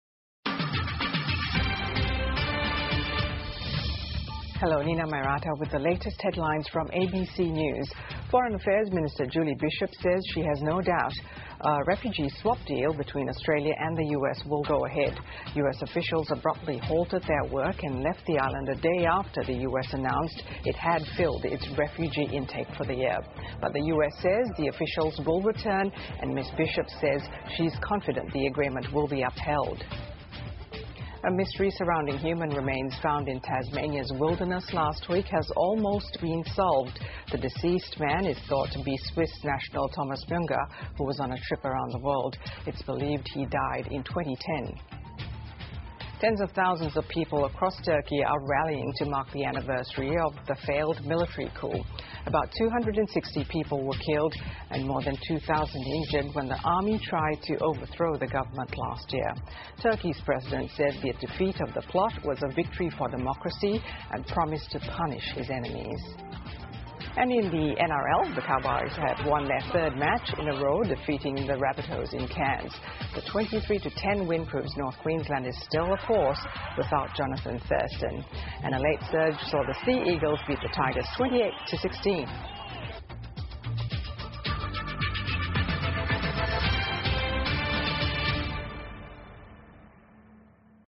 澳洲新闻 (ABC新闻快递) 美方突然暂停美澳难民安置工作 土耳其未遂政变一周年 听力文件下载—在线英语听力室